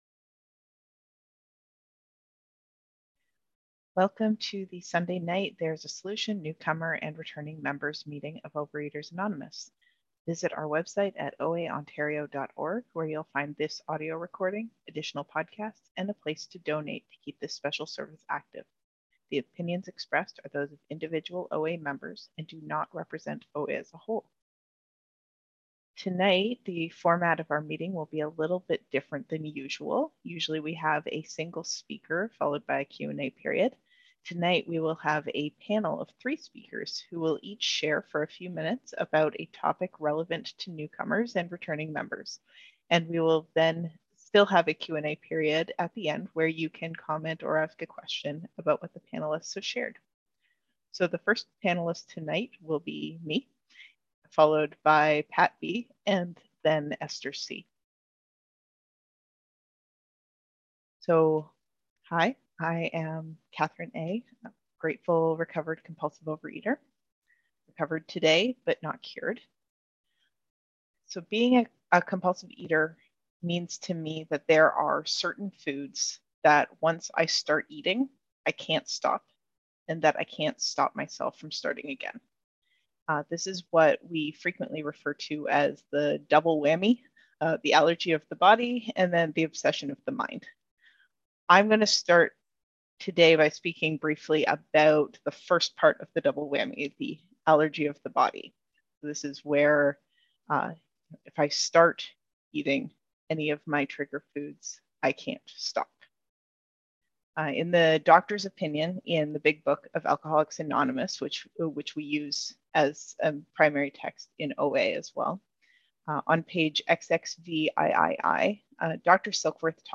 OA Newcomer Meeting - Panel Discussion 2023-01-15